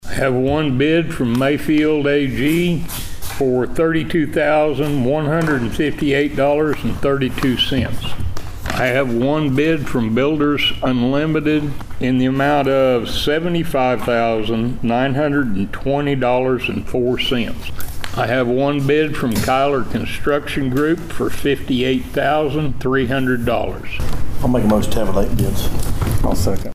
The Board of Osage County Commissioners convened for a brief meeting on Monday morning.
Bids were also tabulated for the relocation of a section of the grandstands at the fairgrounds. District three commissioner Charlie Cartwright goes over the three bidders